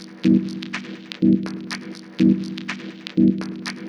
Em (E Minor - 9A) Free sound effects and audio clips
• techno curly mid synth loop.wav
techno_curly_mid_synth_loop_2w7.wav